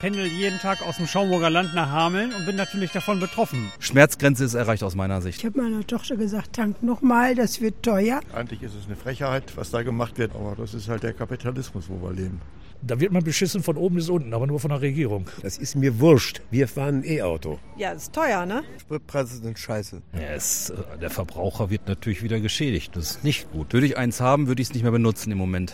Weserbergland: Umfrage zu den gestiegenen Sprirpreisen
weserbergland-umfrage-zu-den-gestiegenen-sprirpreisen.mp3